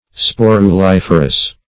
Search Result for " sporuliferous" : The Collaborative International Dictionary of English v.0.48: Sporuliferous \Spor`u*lif"er*ous\ (-[-u]*l[i^]f"[~e]r*[u^]s), a. [Sporule + -ferous.]